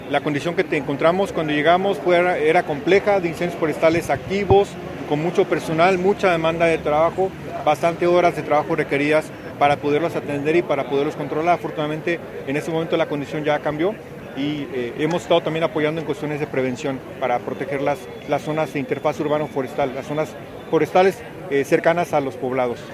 Por ello, se llevó a cabo una ceremonia en agradecimiento y despedida de los brigadistas.